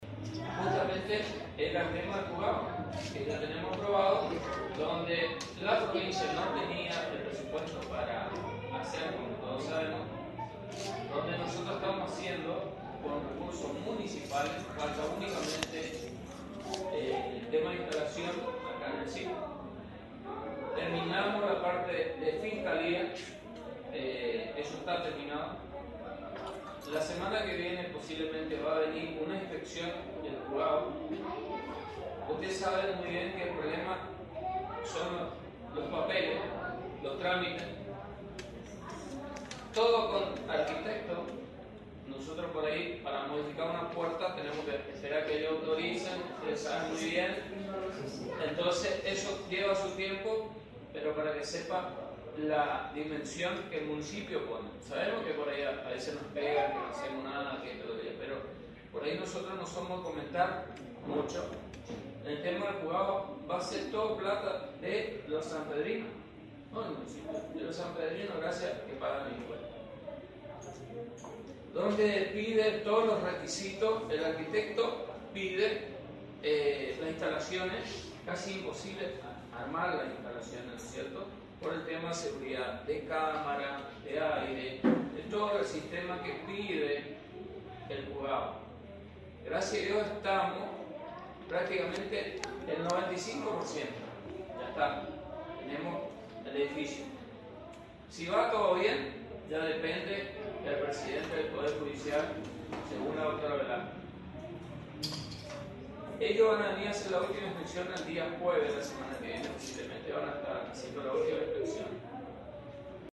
INTENDENTE-jusgado-provincial.mp3